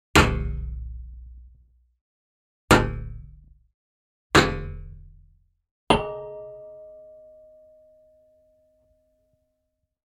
Звуки металлических ударов
Звук удара кулаком по металлической поверхности